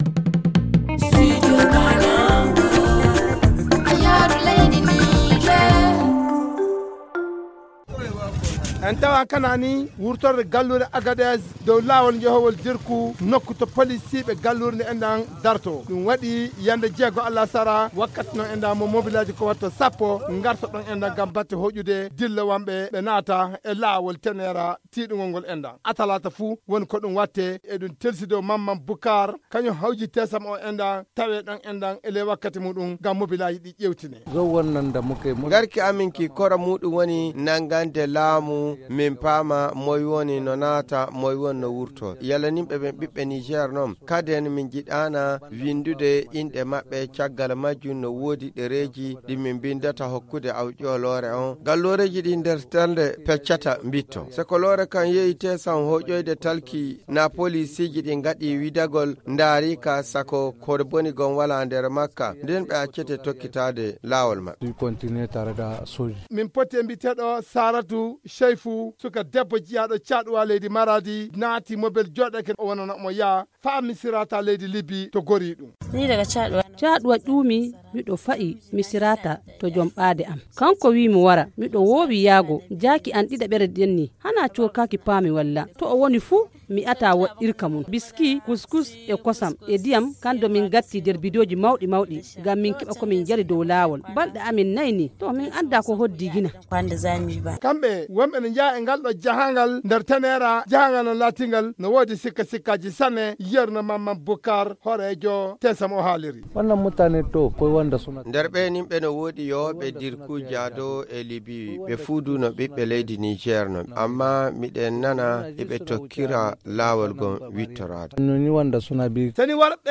Reportage sur la traversée du désert par des migrants en partance vers le Maghreb - Studio Kalangou - Au rythme du Niger
Voici son reportage.